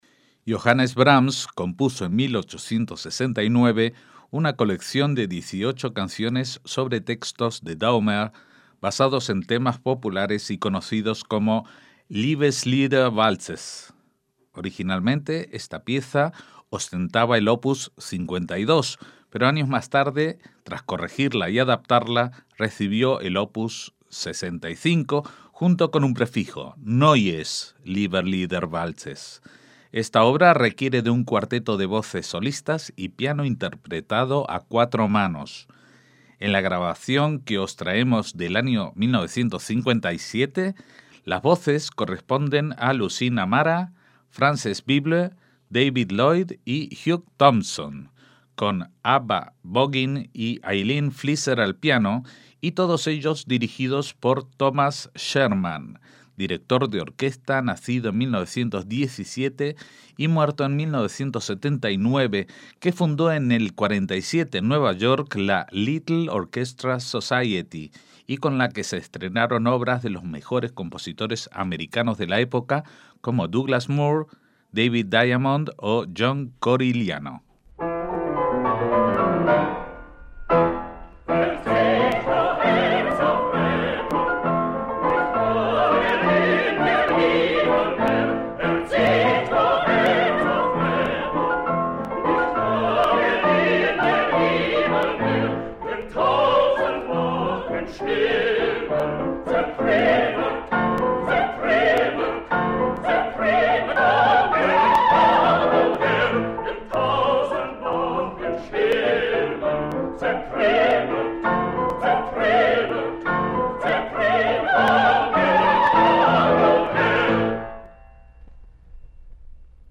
MÚSICA CLÁSICA - Thomas Scherman fue un director de orquesta estadounidense que vivió entre 1917 y 1979, fundador de la Little Orchestra Society, hijo de judío y madre gentil, fue alumno de Otto Klemperer en Columbia.
Los valses son una colección de canciones de amor en estilo Ländler para voces y piano a cuatro manos.